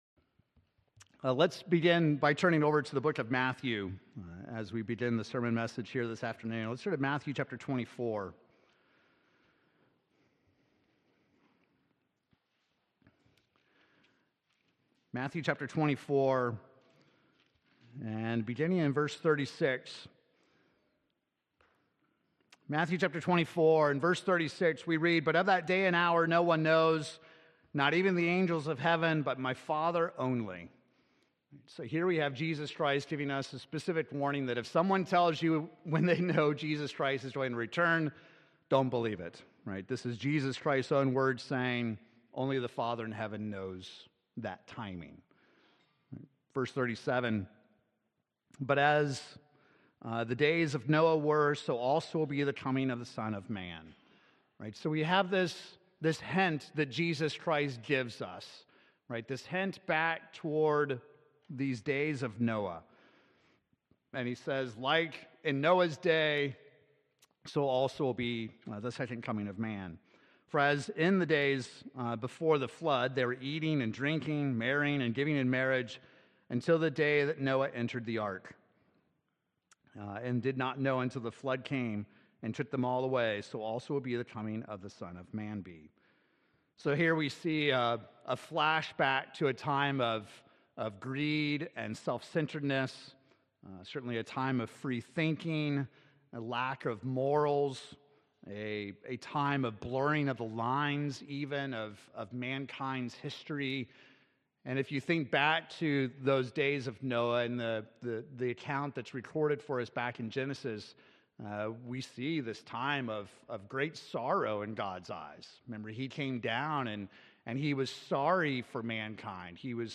In this part-two sermon, let's explore the subtlety that Satan the Devil uses to influence mankind. It's a triad of tactics as old as the Garden of Eden.